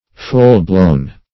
Full-blown \Full"-blown`\, a.